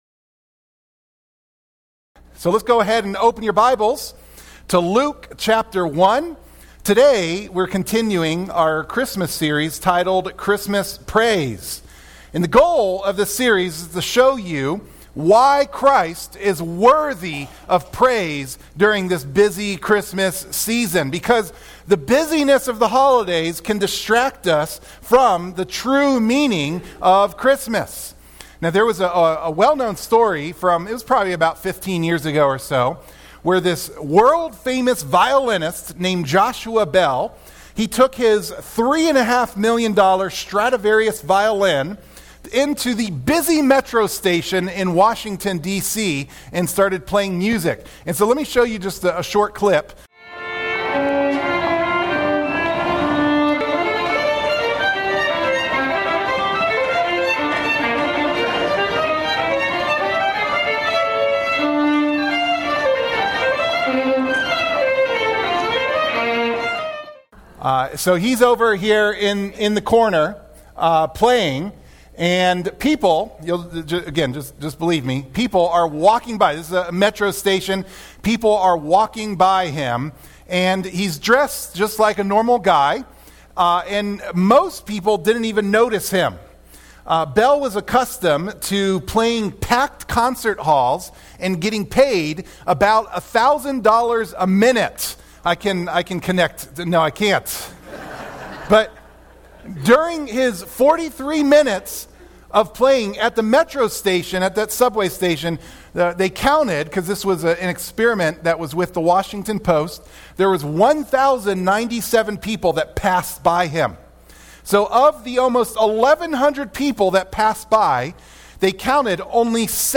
Sermon Clip The message notes for the sermon can be downloaded by clicking on the “save” button.